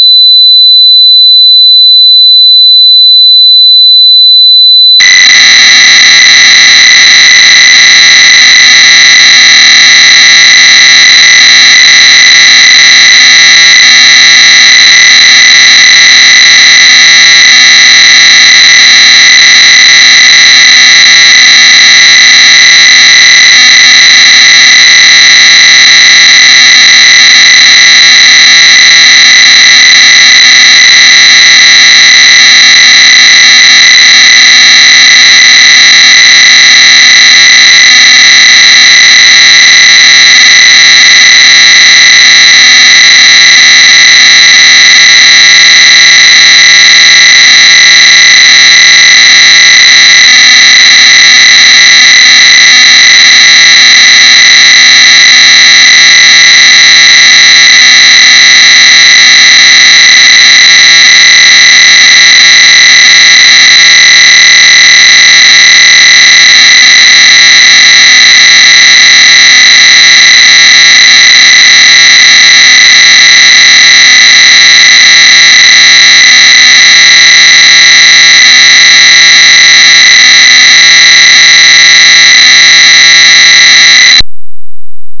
The source code can be converted to a WAV file using the Pocket Tools to transfer the program to the Sharp Pocket PC.
The *.wav file must be loaded on the PC-1350 / PC-1360 with CLOAD.